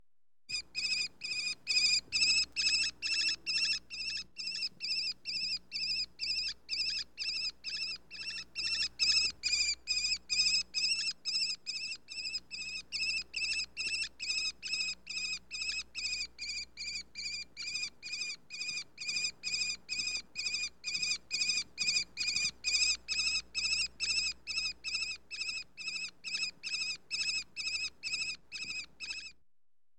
Sperber Ruf
Sperber-Ruf-Voegel-in-Europa.mp3